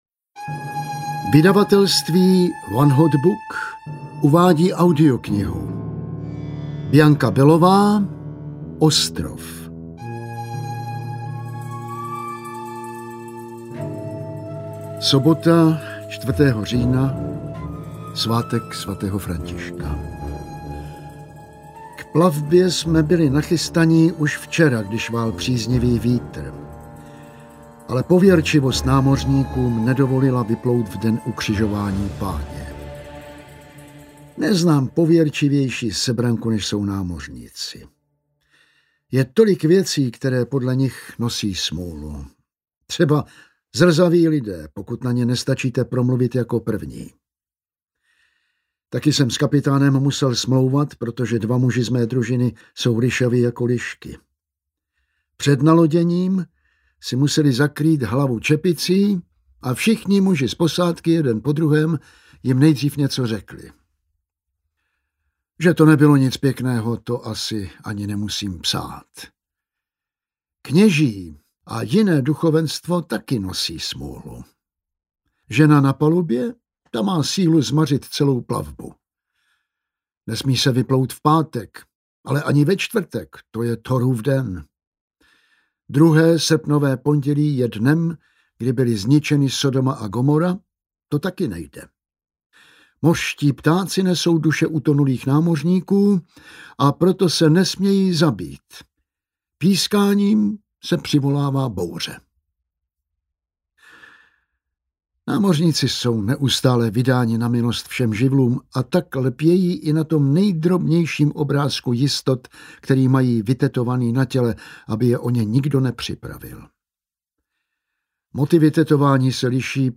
Ostrov audiokniha
Ukázka z knihy
• InterpretTaťjana Medvecká, Jan Vlasák